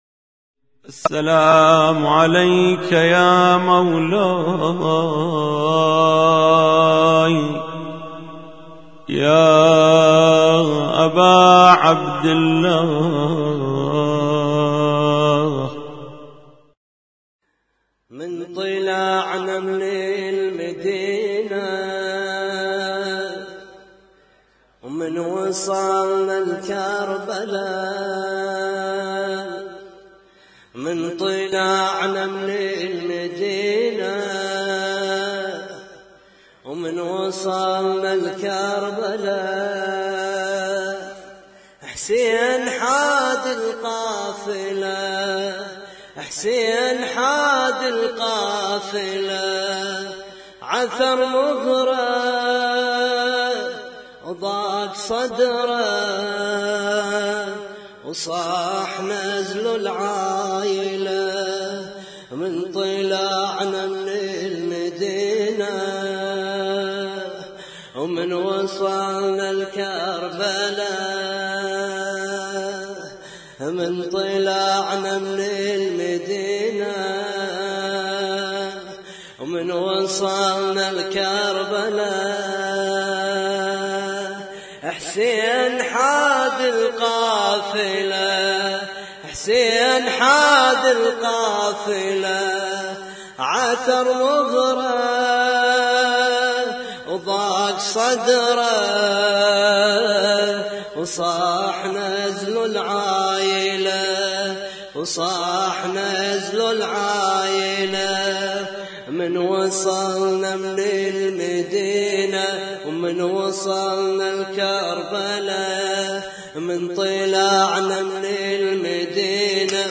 لطم ليلة 4 محرم 1436